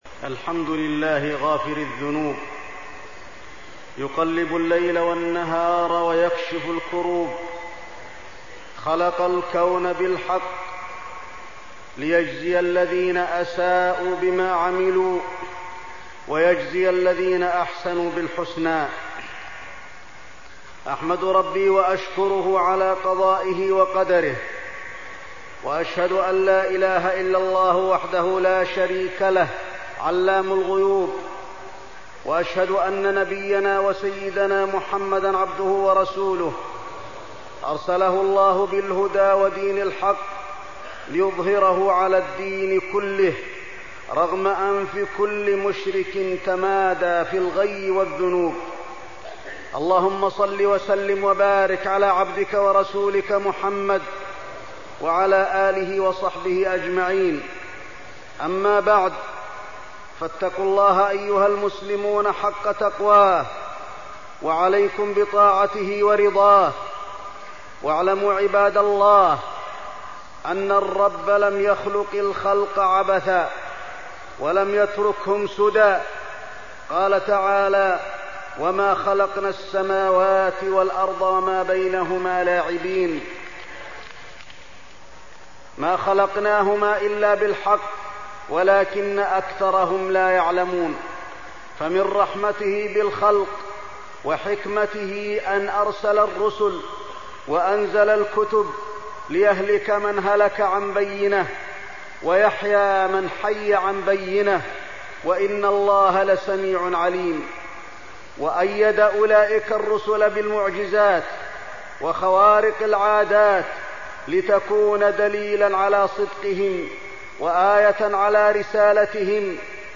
تاريخ النشر ٢٦ شوال ١٤١٦ هـ المكان: المسجد النبوي الشيخ: فضيلة الشيخ د. علي بن عبدالرحمن الحذيفي فضيلة الشيخ د. علي بن عبدالرحمن الحذيفي معجزات النبي صلى الله عليه وسلم